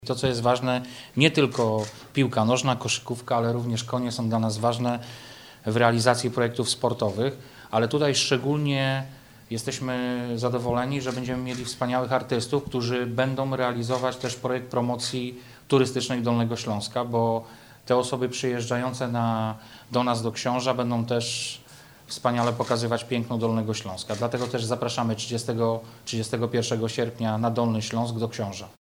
– Sport jest bardzo ważnym elementem w naszej działalności – mówi Wojciech Bochnak, Wicemarszałek Województwa Dolnośląskiego.